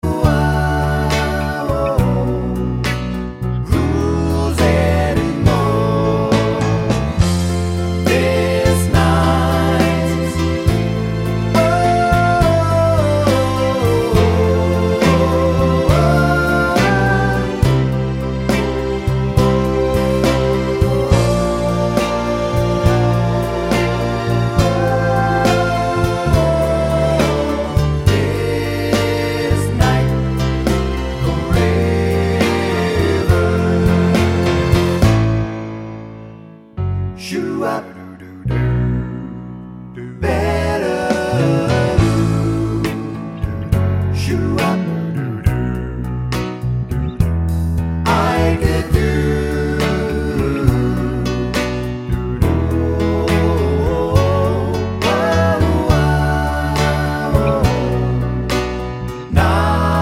no sax solo Pop (1980s) 4:16 Buy £1.50